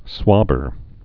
(swŏbər)